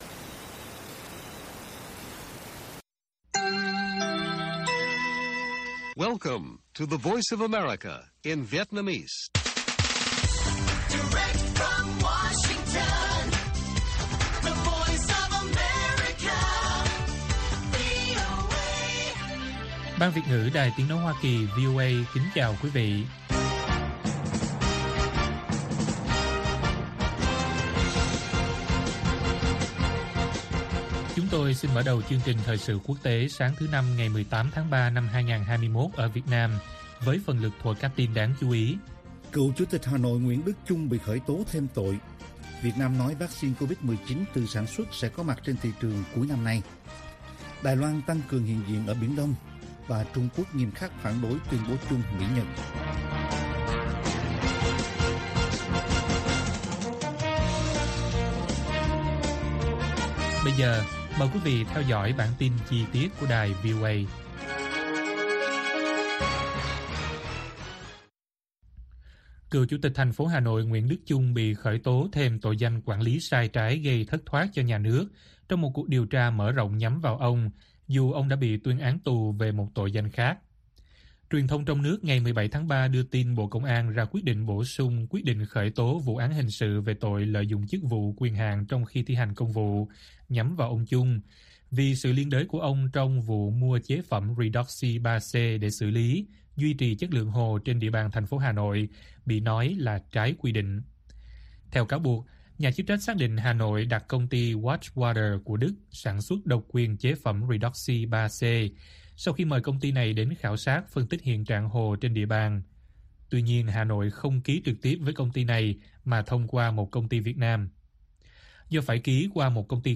Bản tin VOA ngày 18/3/2021